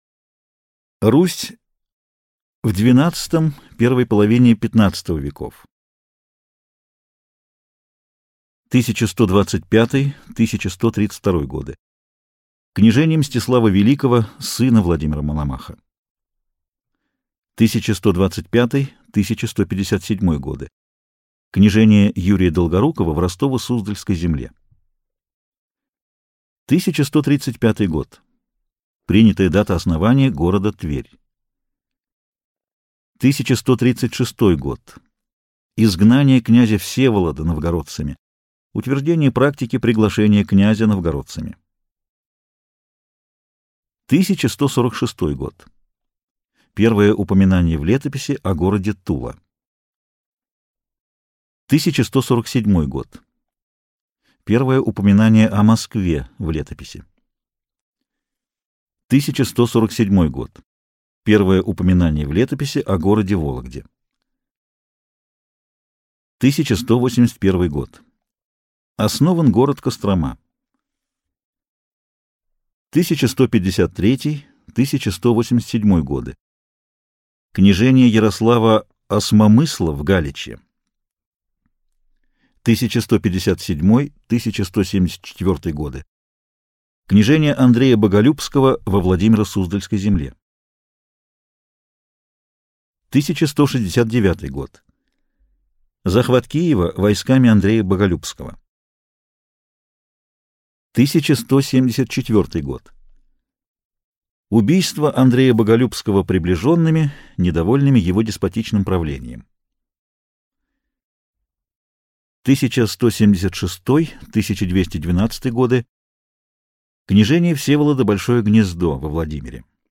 Аудиокнига История России: События и даты | Библиотека аудиокниг